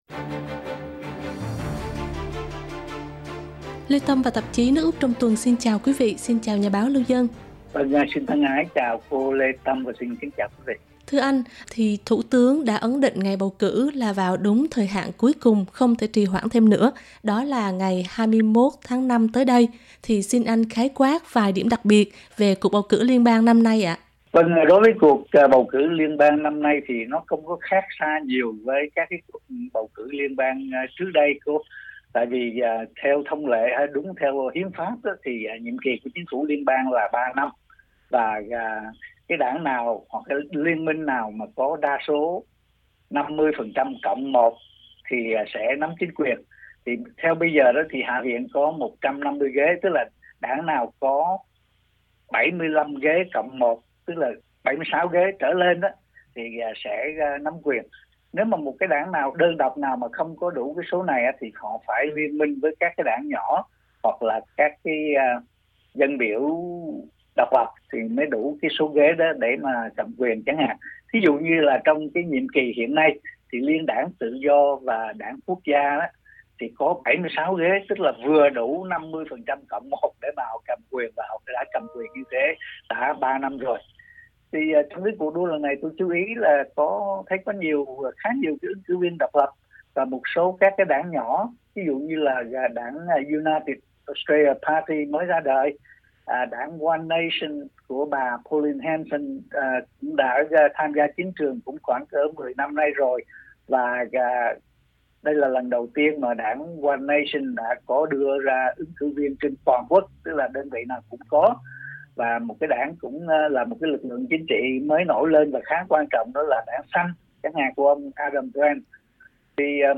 bài bình luận